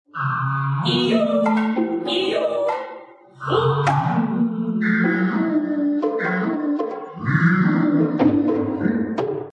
Japanese Voices